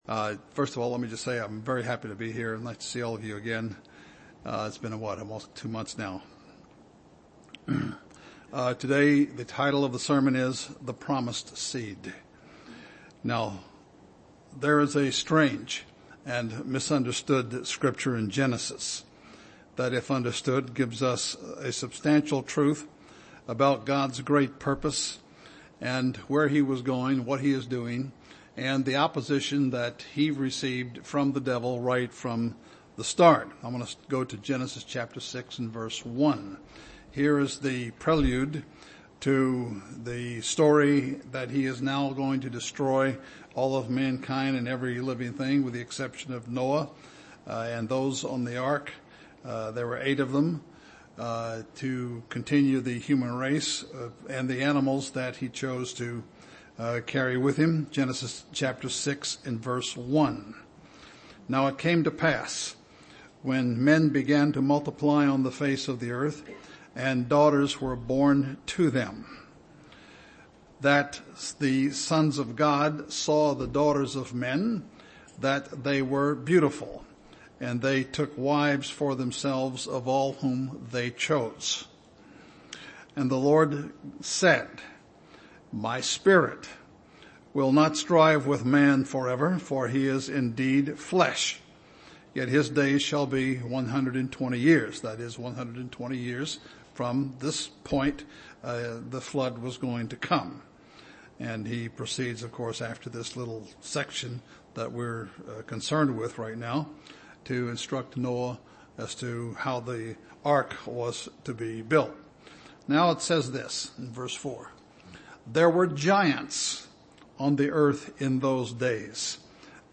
Given in Chicago, IL
UCG Sermon